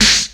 • 00s Hip-Hop Snare Drum Sound F# Key 10.wav
Royality free snare drum tuned to the F# note. Loudest frequency: 4227Hz
00s-hip-hop-snare-drum-sound-f-sharp-key-10-QlT.wav